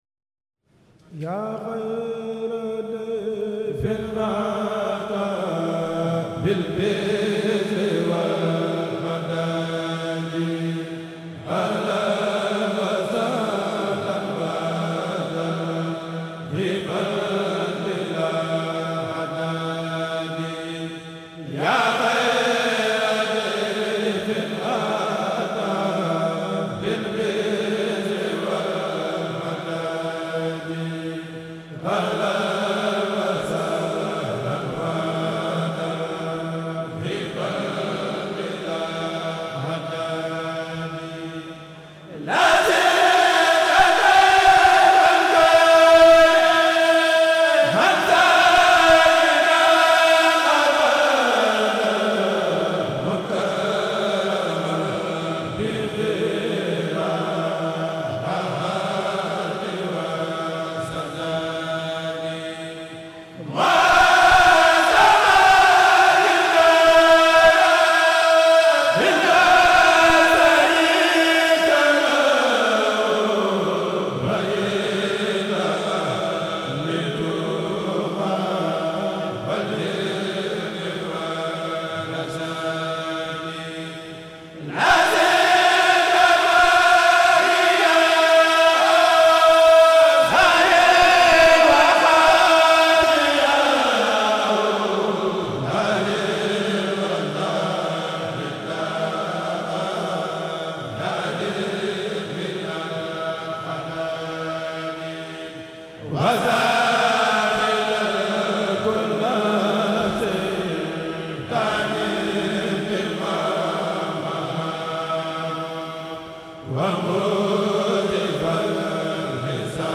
AL-Mashrabu Shafi : Hizbut Tarqiyyah Ramadan 2023 - 1444H a la Résidence cheikhoul Khadim de Touba en Présence du Khalife General des Mourides Serigne Mountakha Mbacké (KHASSIDA MP3)